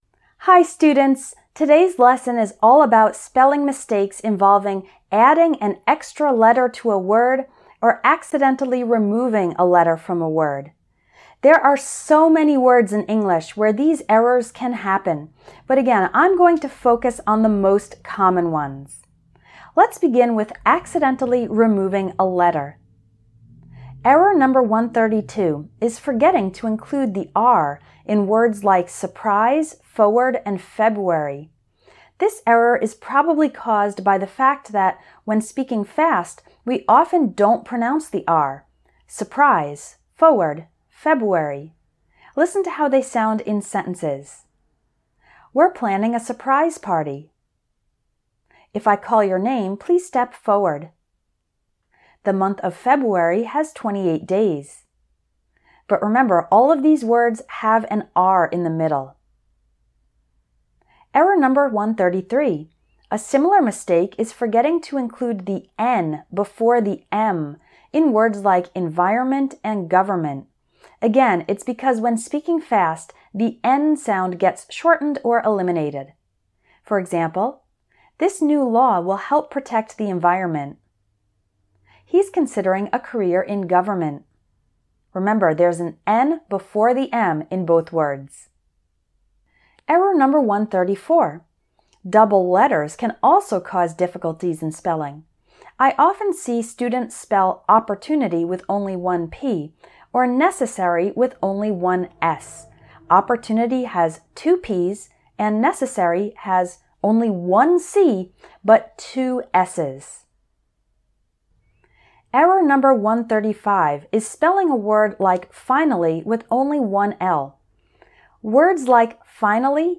Lesson-31-Errors-involving-adding-or-removing-a-letter.mp3